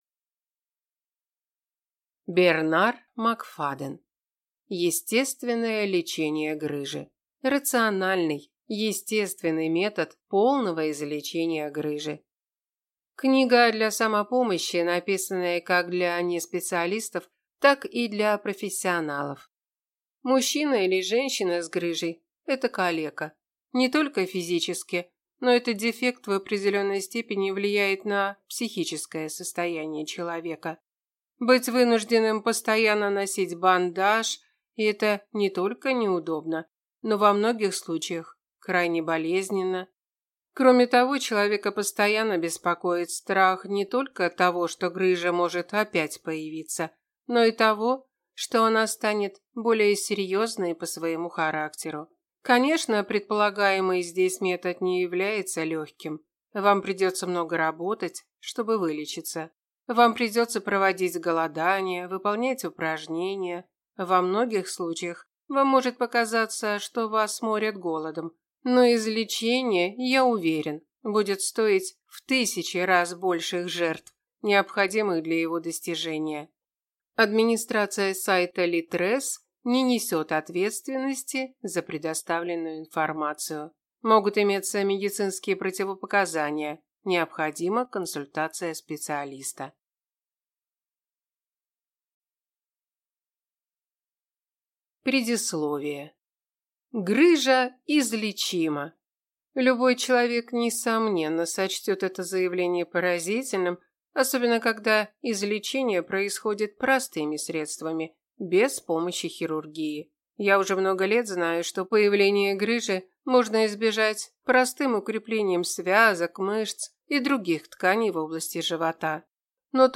Аудиокнига Естественное лечение грыжи. Рациональный, естественный метод полного излечения грыжи | Библиотека аудиокниг